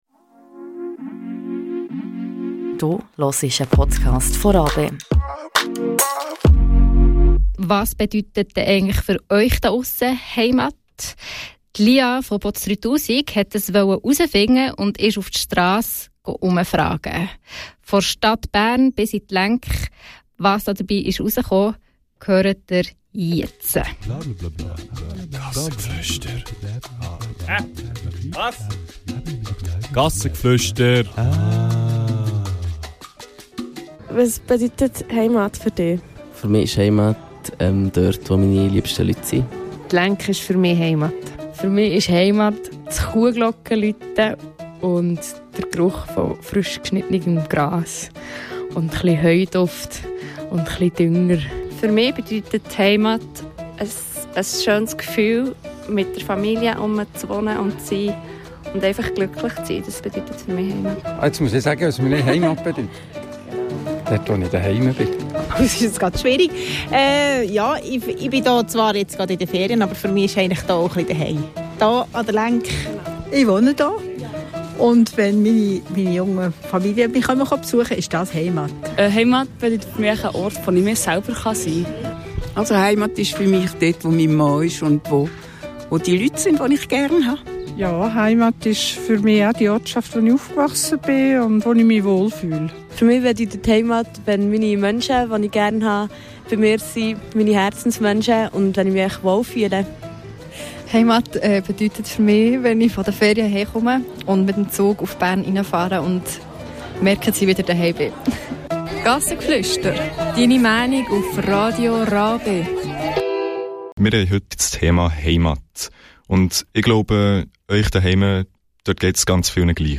Strassenumfrage und Gedanken zu "Heimat"